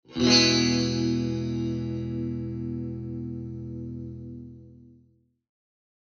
Clean Sound Guitar
Cleanジャラーン(E) 118.27 KB